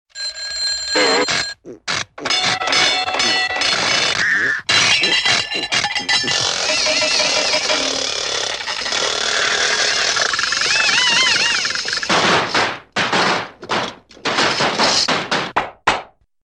Звуки поломки автомобиля
Звук поломанной машины будто из мультика